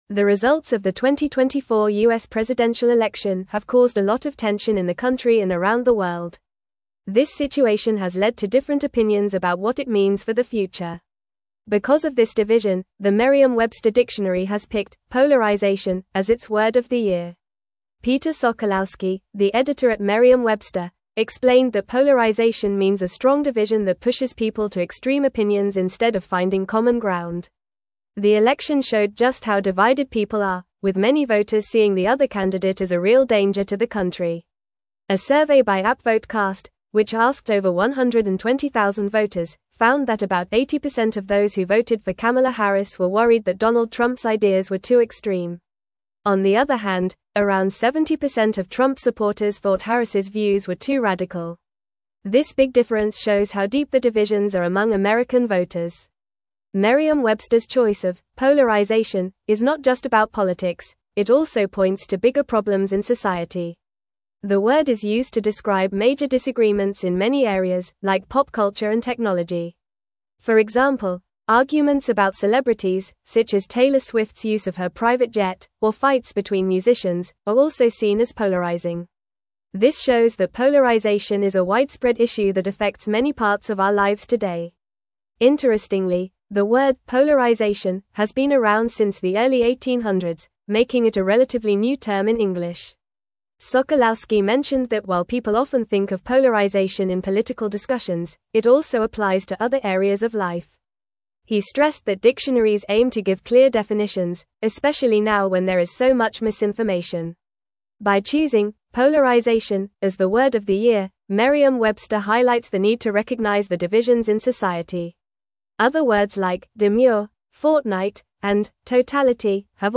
Fast
English-Newsroom-Upper-Intermediate-FAST-Reading-Polarization-Named-Merriam-Websters-Word-of-the-Year.mp3